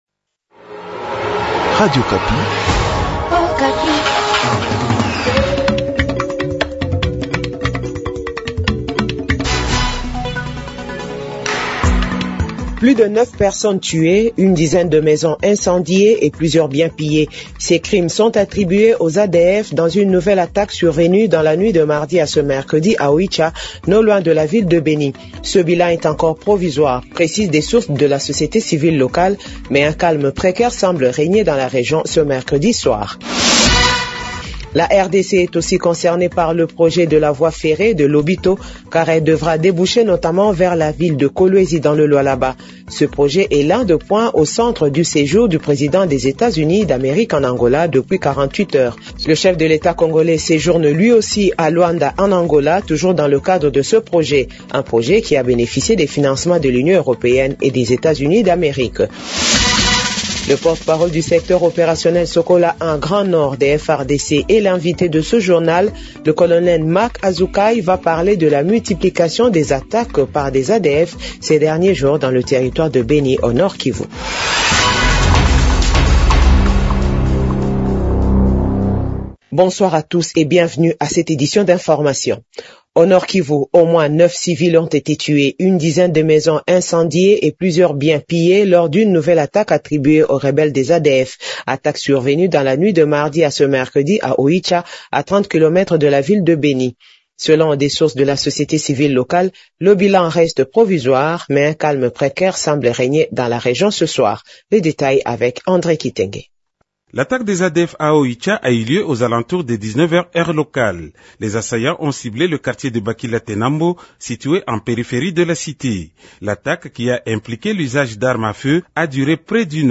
JOURNAL FRANÇAIS DE 18H00